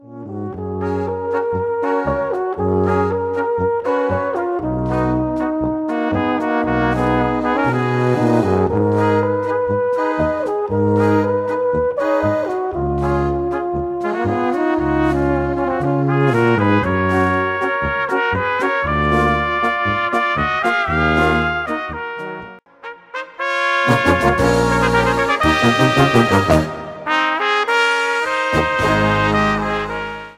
Gattung: Polka für kleine Besetzung
Besetzung: Kleine Blasmusik-Besetzung